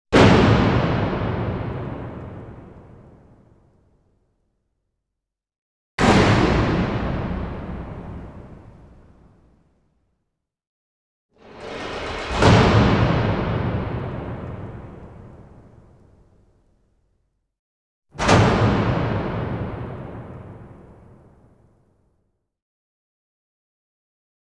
This background music slapped and weirdly fit this game.